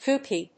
/ˈkuki(米国英語), ˈku:ki:(英国英語)/
音節kook・y, kook・ie 発音記号・読み方/kúːki/